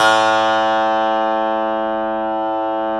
53s-pno04-A0.aif